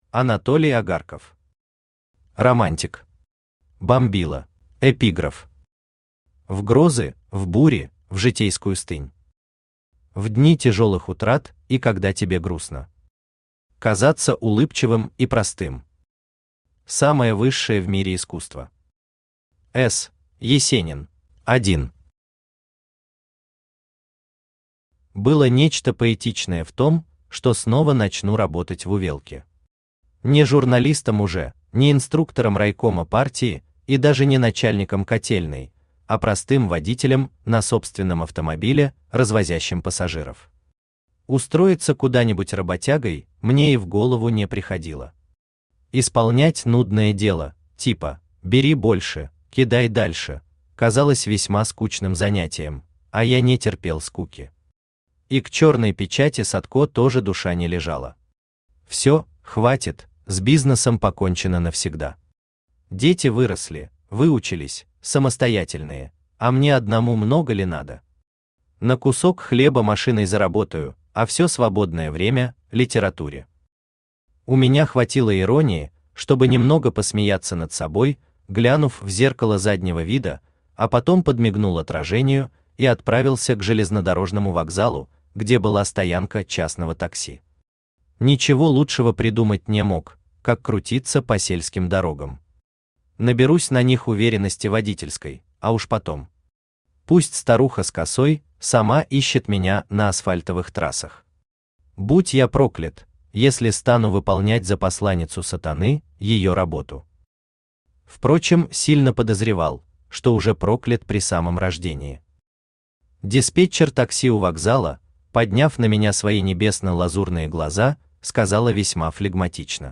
Аудиокнига Романтик. Бомбила | Библиотека аудиокниг
Aудиокнига Романтик. Бомбила Автор Анатолий Агарков Читает аудиокнигу Авточтец ЛитРес.